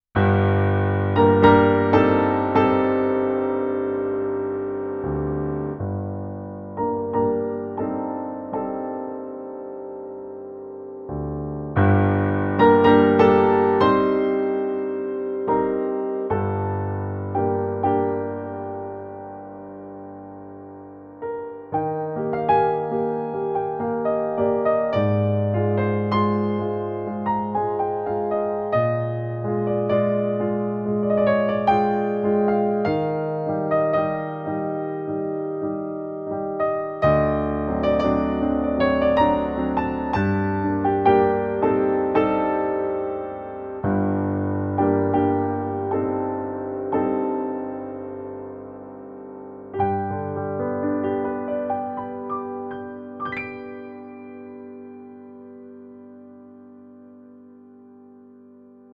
Neben dem hauseigenen Yamaha CFX Sound (Konzertflügel) steht noch ein etwas weicherer und für klassisches Spiel prädestinierter Bösendorfer Klang zur Wahl.
Bösendorfer Grand Piano
yamaha_csp-170_test_demo02_boesendorfer.mp3